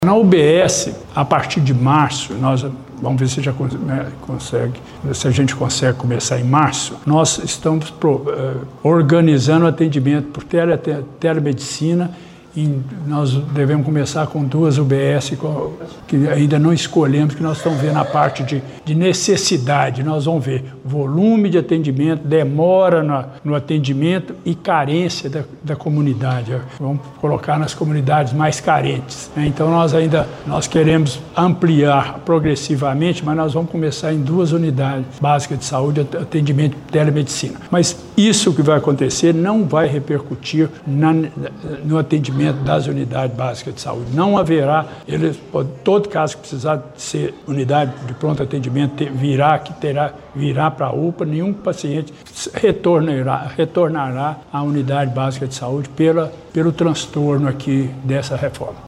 Durante coletiva de imprensa realizada nesta quinta-feira, 29, na UPA Pediátrica de Pará de Minas, o secretário municipal de Saúde, Gilberto Denoziro Valadares da Silva, anunciou alterações pontuais na logística de atendimento da unidade.